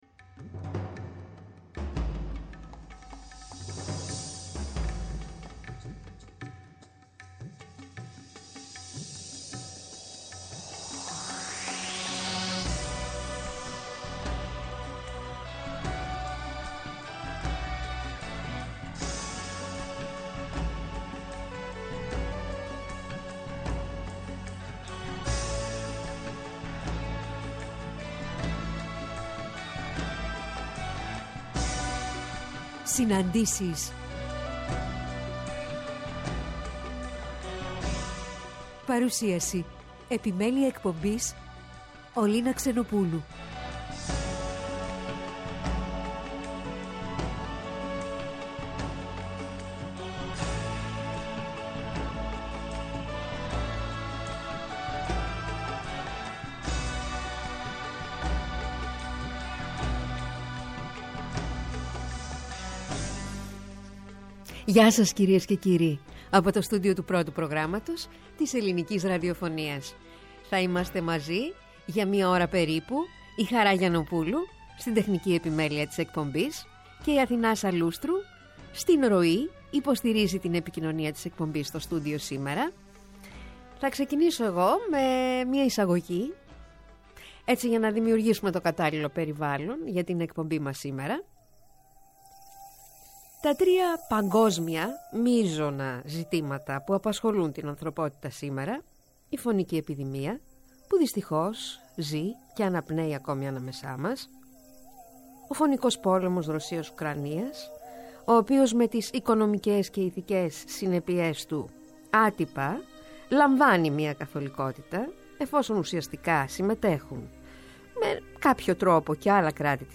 Στις ΣΥΝΑΝΤΗΣΕΙΣ στο ΠΡΩΤΟ ΠΡΟΓΡΑΜΜΑ την Κυριακή 12-02-23 και ώρα 16:00-17:00 καλεσμένος τηλεφωνικά: